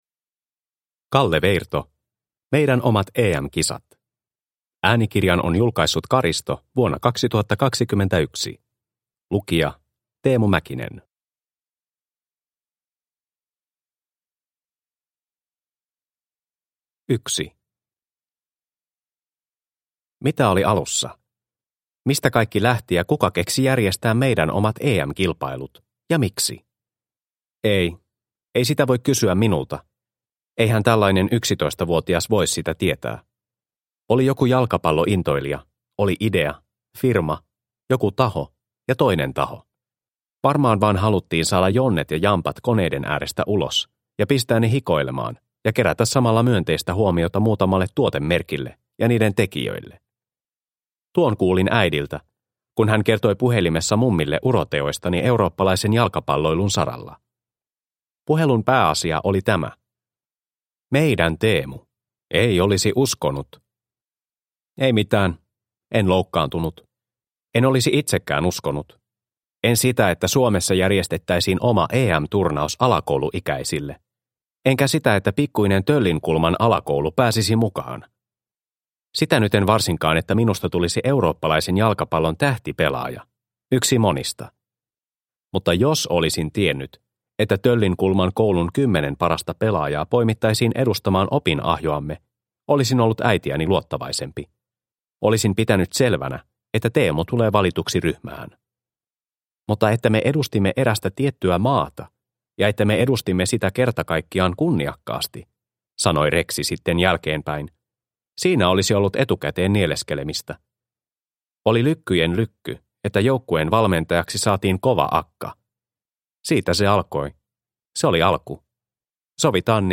Meidän omat EM-kisat – Ljudbok – Laddas ner